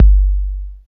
39 808 KICK.wav